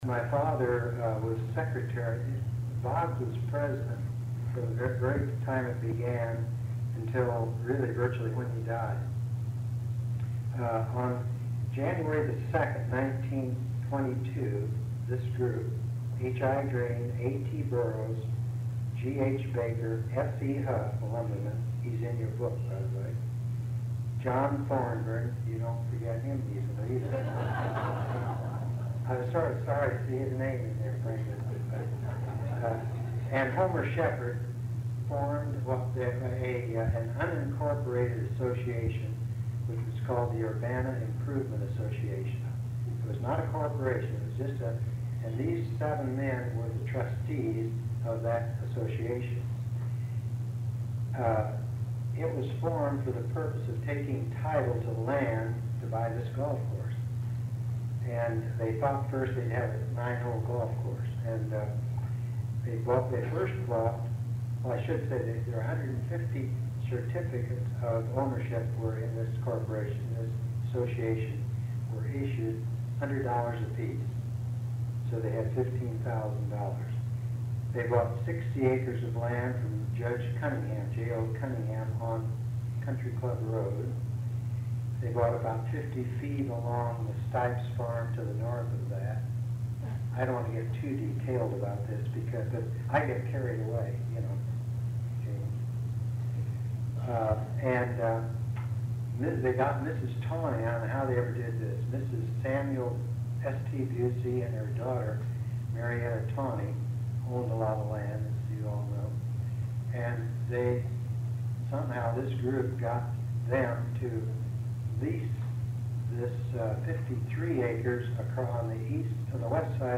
Recording of Annual Meeting